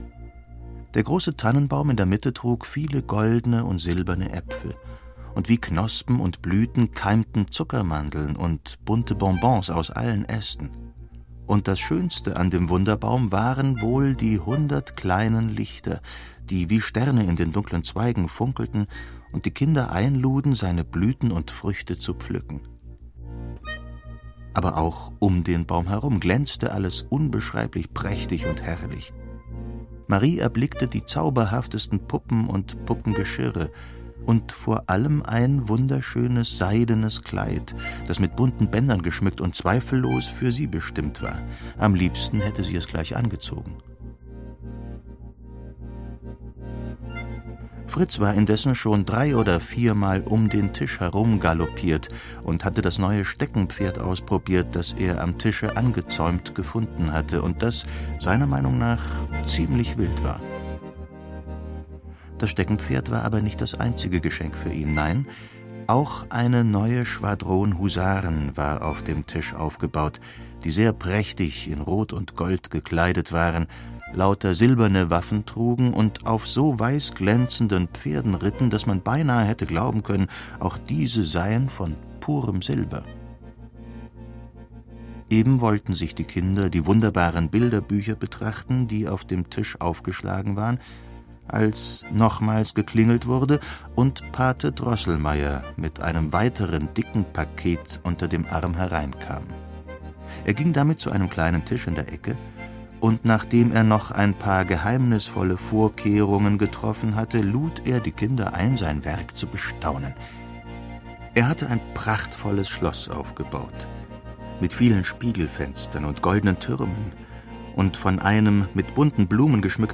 Ulrich Noethen (Sprecher)